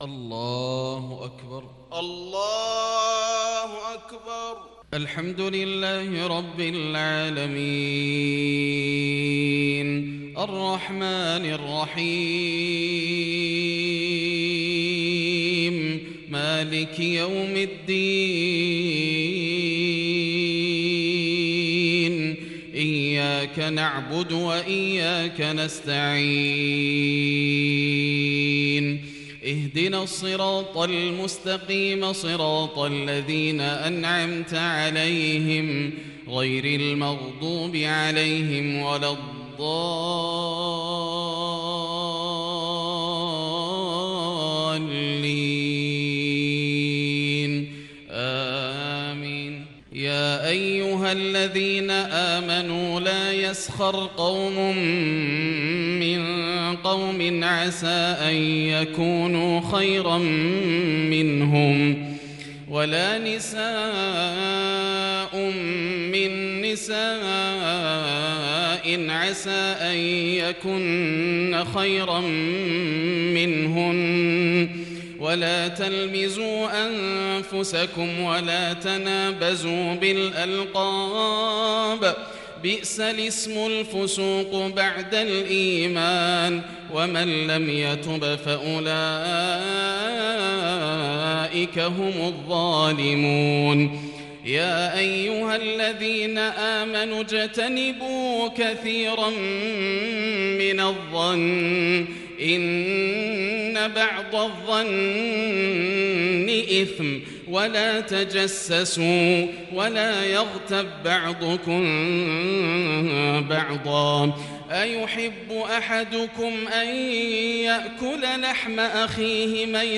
صلاة العشاء للشيخ ياسر الدوسري 26 محرم 1442 هـ
تِلَاوَات الْحَرَمَيْن .